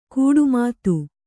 ♪ kūḍu mātu